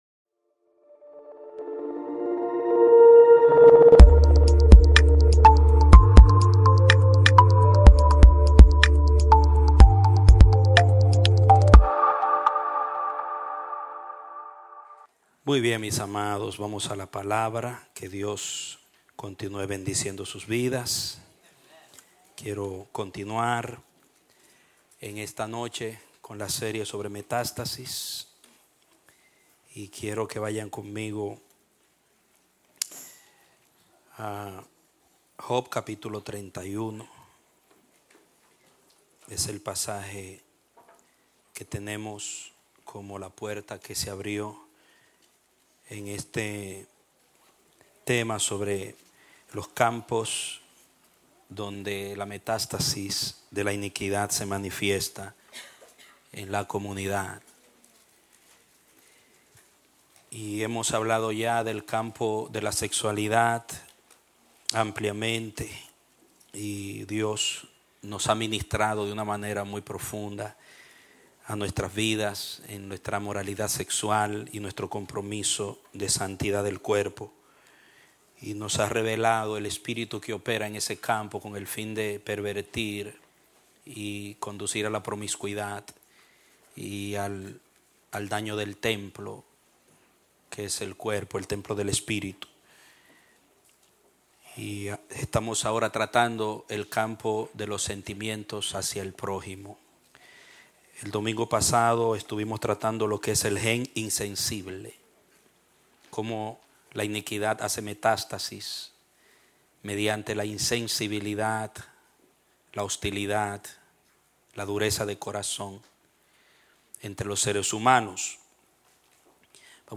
Un mensaje de la serie "Metástasis."